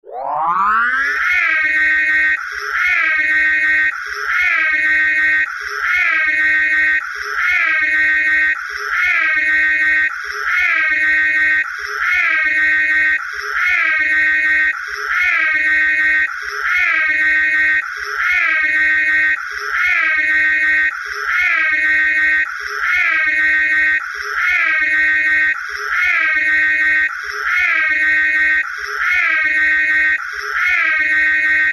Звук сирены машины охотников за привидениями включается на 30 секунд